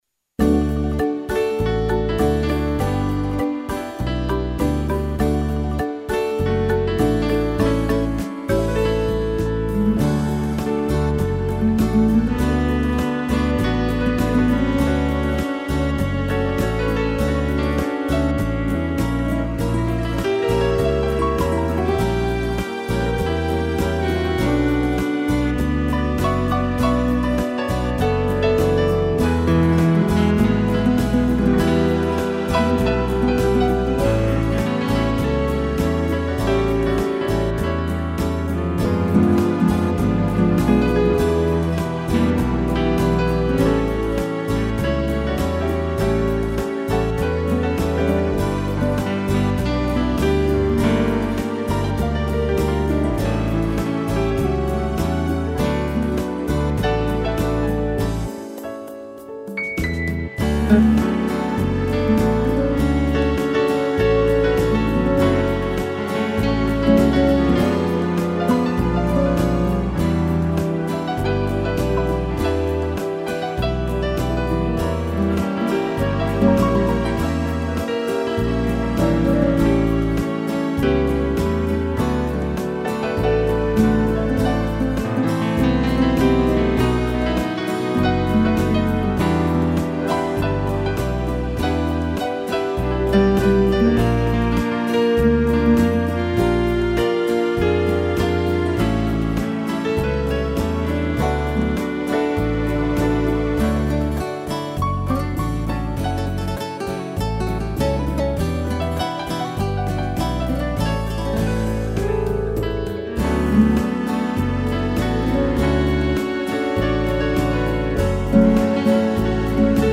piano e cello
(instrumental)